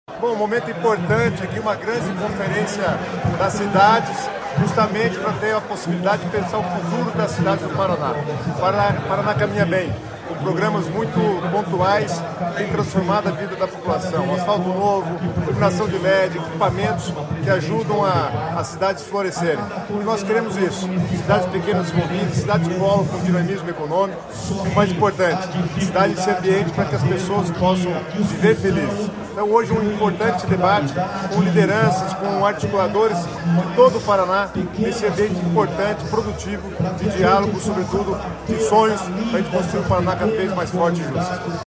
Sonora do secretário das Cidades, Guto Silva, sobre a 7ª Conferência Estadual das Cidades